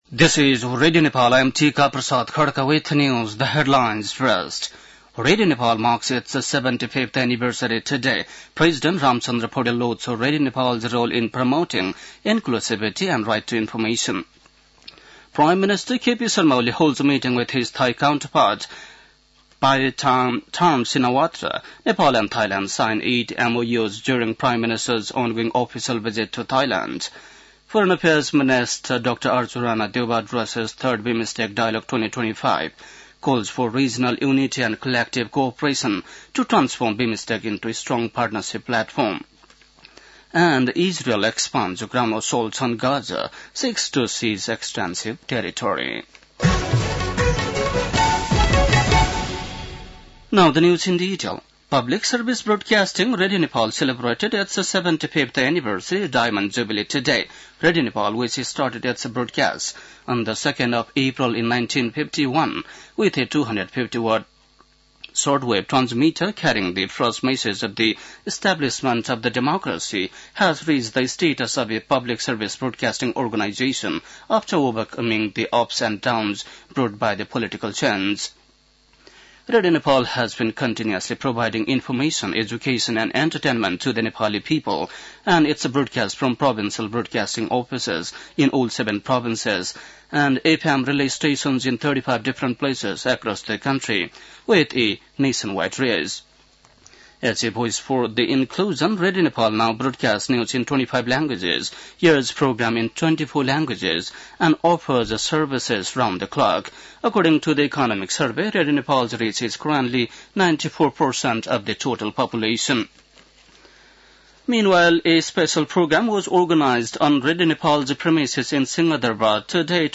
बेलुकी ८ बजेको अङ्ग्रेजी समाचार : २० चैत , २०८१
8-pm-english-news.mp3